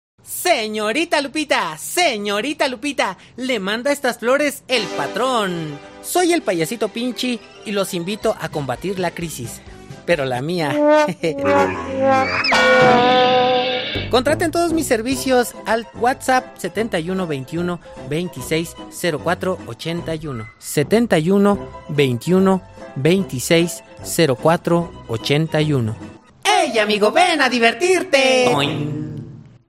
Locación: Cafetería “Coffee Garden".